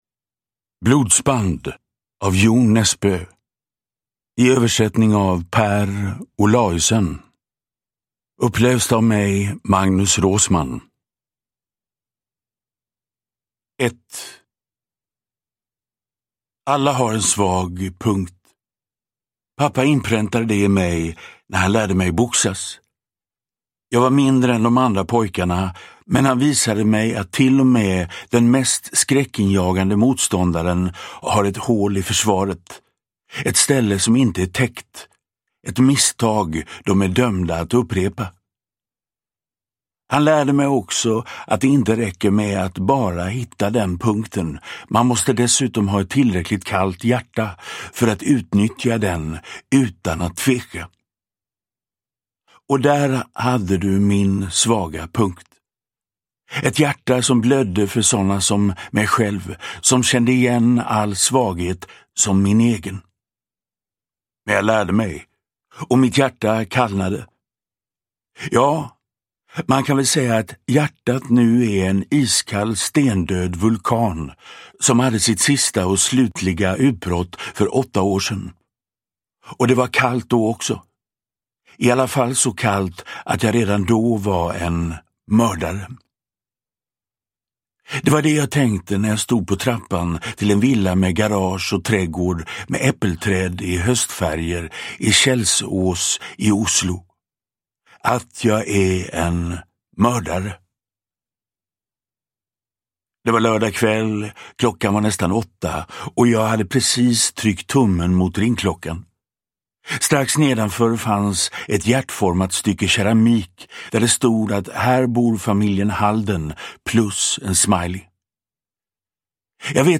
Blodsband – Ljudbok
Uppläsare: Magnus Roosmann